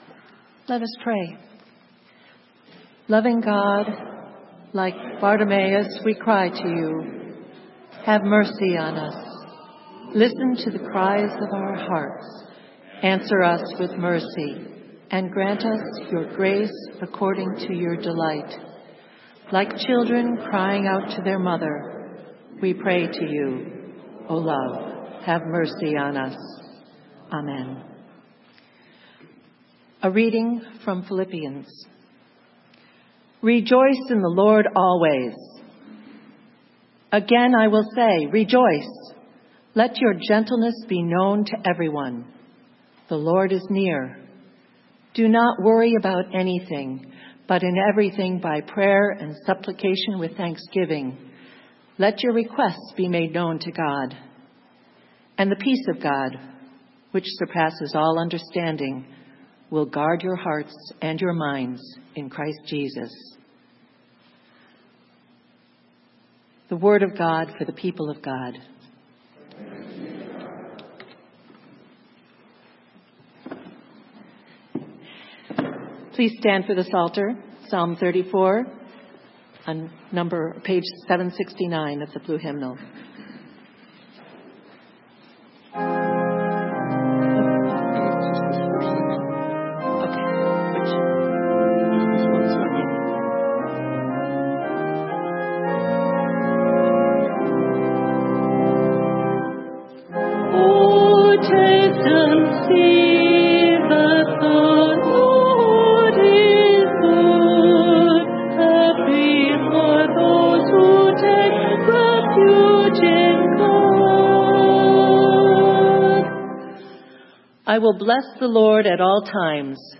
Sermon: The healing of Bartimaeus - St. Matthews UMC